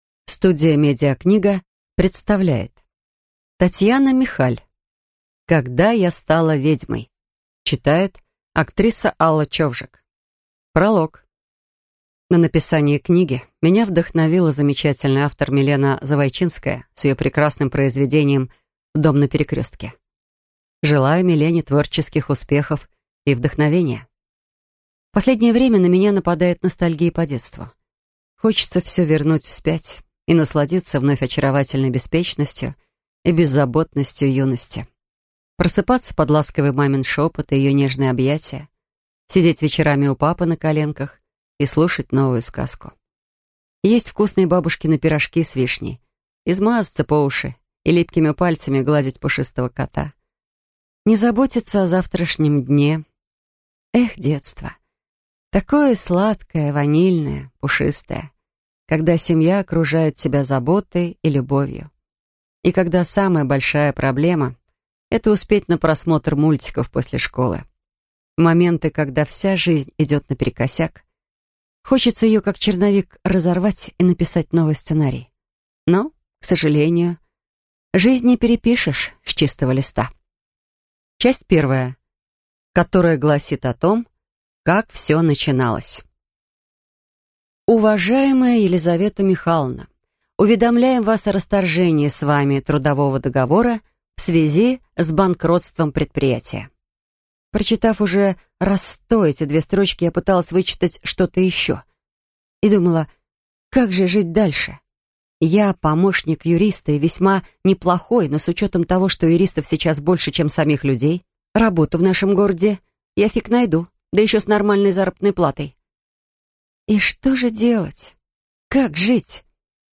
Аудиокнига Когда я стала ведьмой | Библиотека аудиокниг